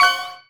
collect_coin_05.wav